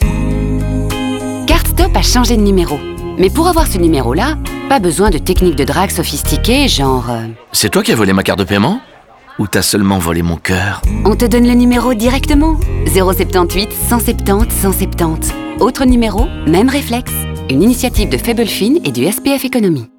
Ce message et le nouveau numéro sont communiqués dans un certain nombre de spots radio et de publications sur les réseaux sociaux, mais aussi dans des endroits très pertinents tels que les écrans des guichets automatiques.